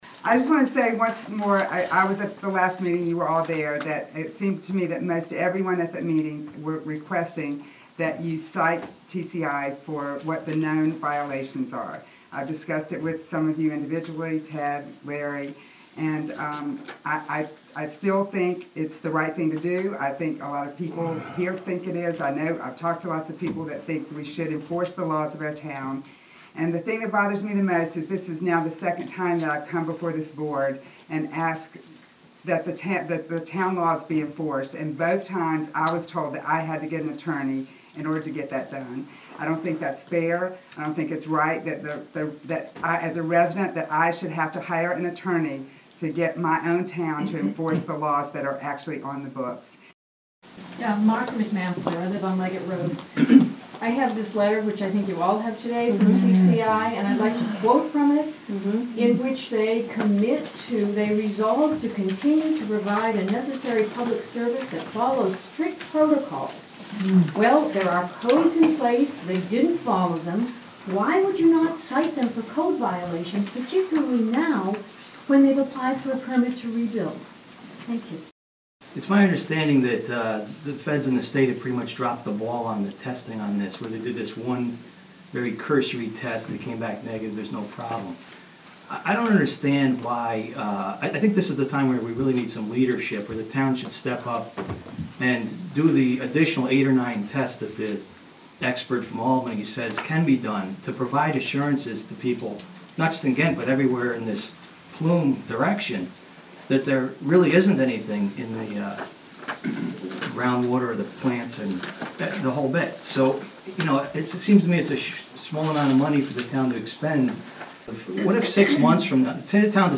Play excerpts (3:30) Ghent local audio town meetings TCI West Ghent chemical fire TCI NY Inc. chemical fire Ghent Town Board industrial fire